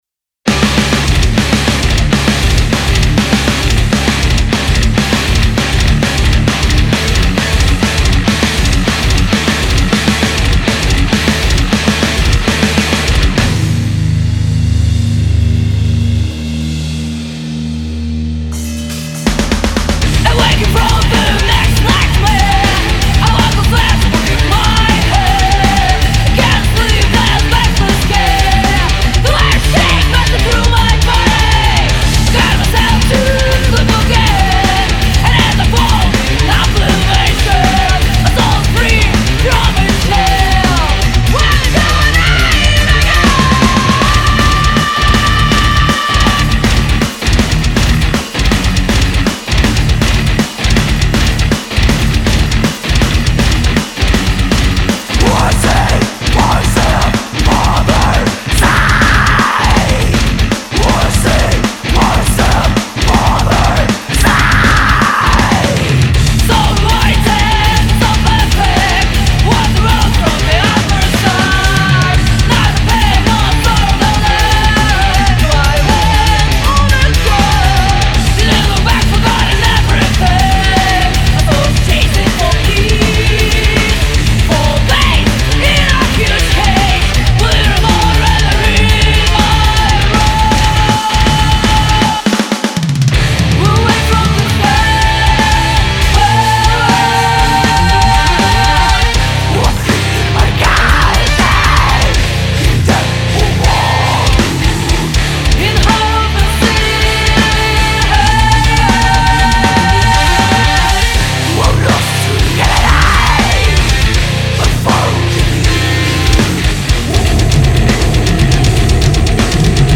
вокал
гитара
ударные